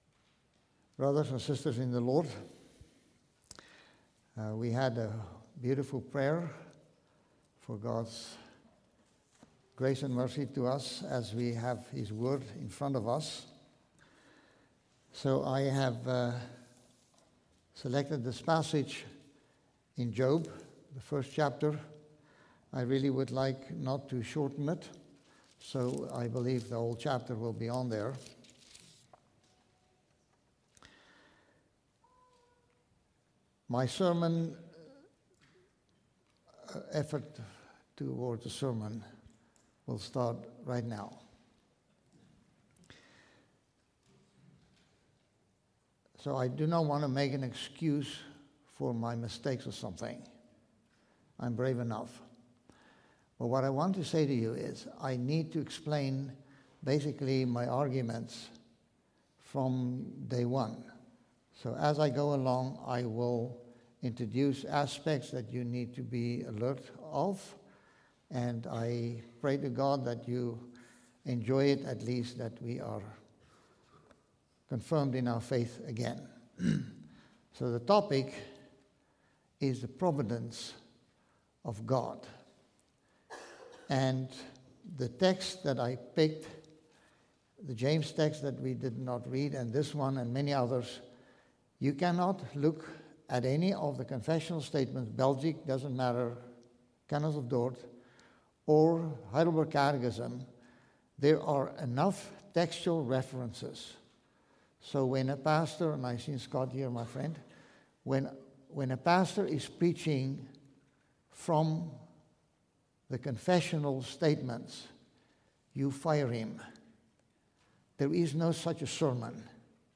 Guest Speaker
Job 1; Heidelberg Catechism 10 & 11 Stand Alone Sermons